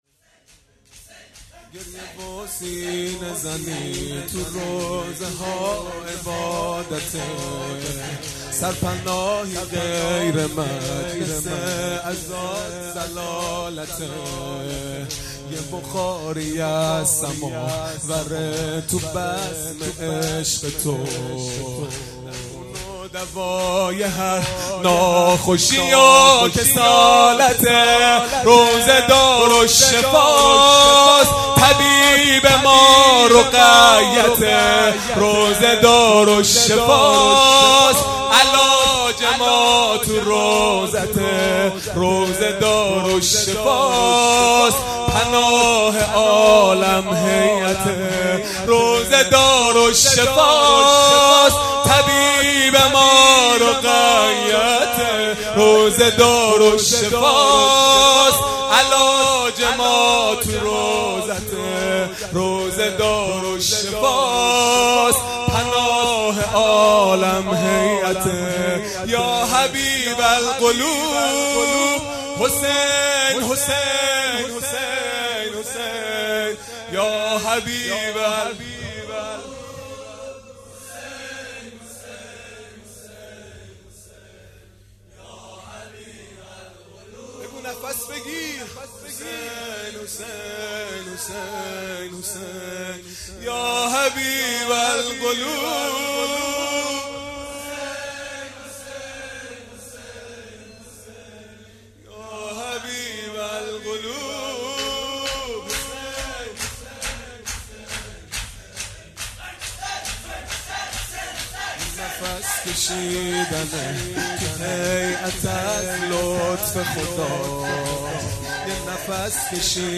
خیمه گاه - هیئت بچه های فاطمه (س) - شور | روضه دارالشفاست
جلسه هفتگی هیات به مناسبت شهادت حضرت حمزه(ع)